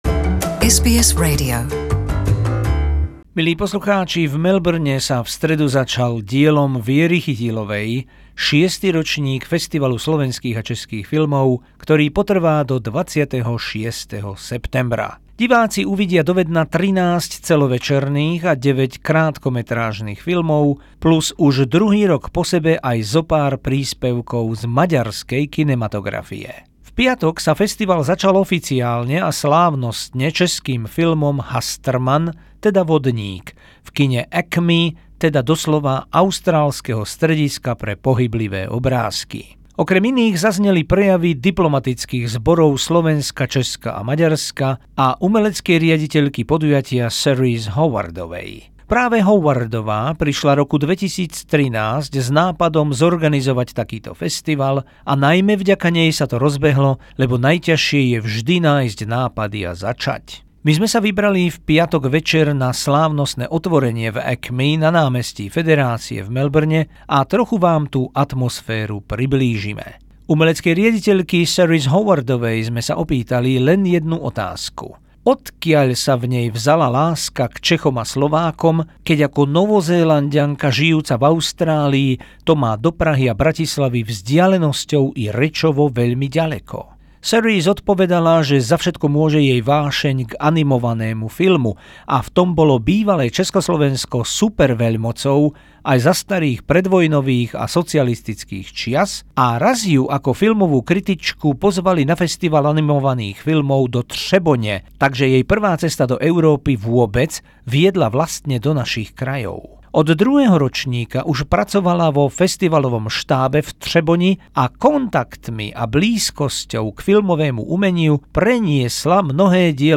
Report from Opening Night at 2018 CASFFA